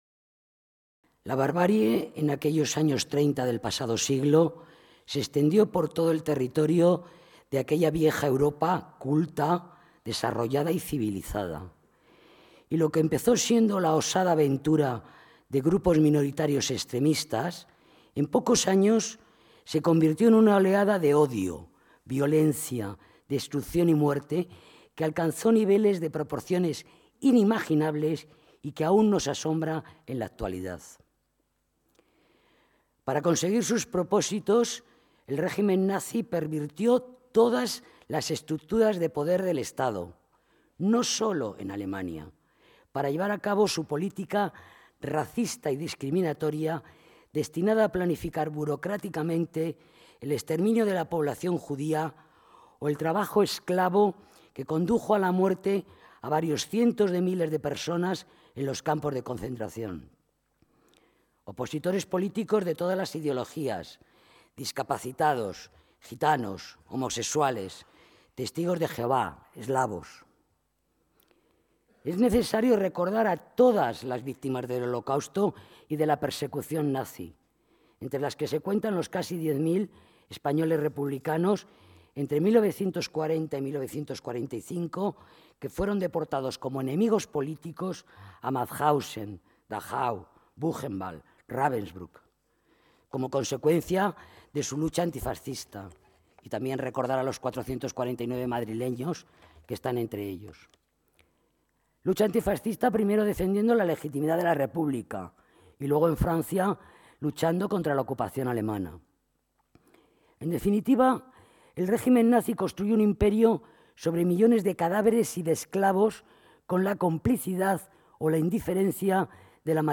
El Ayuntamiento de Madrid ha celebrado hoy, miércoles 23 de enero, en el Palacio de Cibeles, un acto conmemorativo con motivo del Día Internacional en Memoria de las Víctimas del Holocausto.